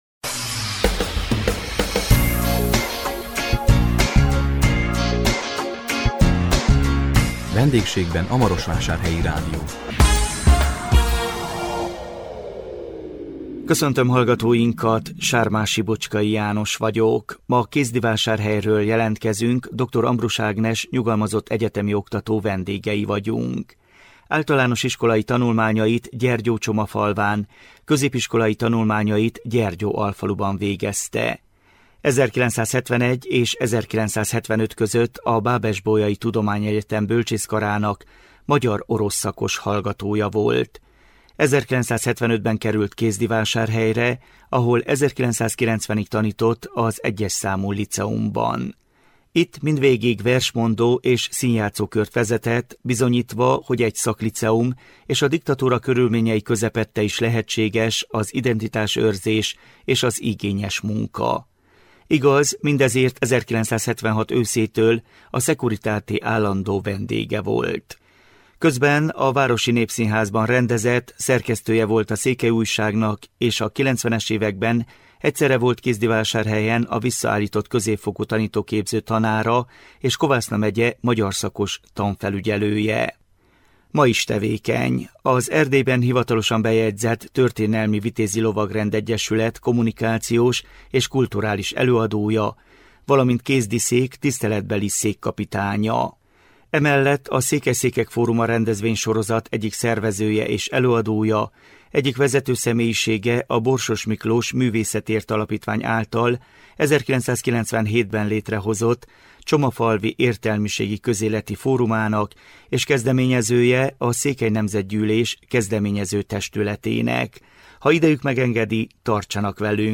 A 2025 december 4-én közvetített VENDÉGSÉGBEN A MAROSVÁSÁRHELYI RÁDIÓ című műsorunkkal Kézdivásárhelyről jelentkeztünk,